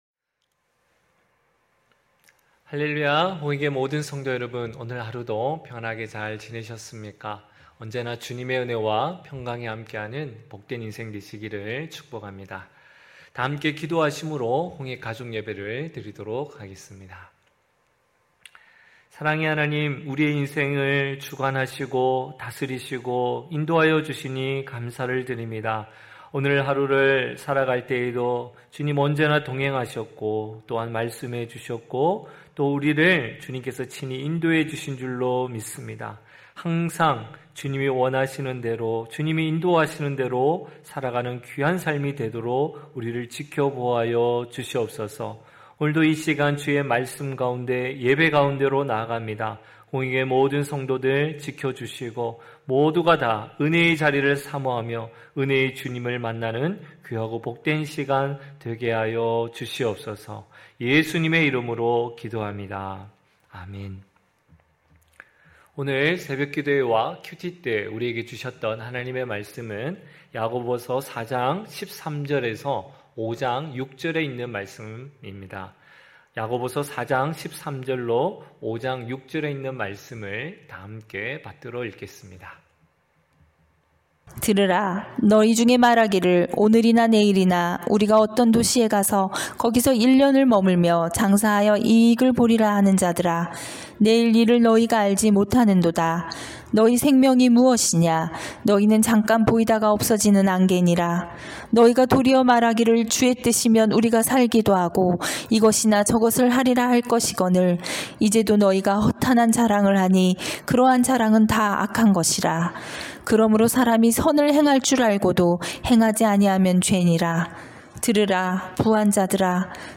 9시홍익가족예배(7월9일).mp3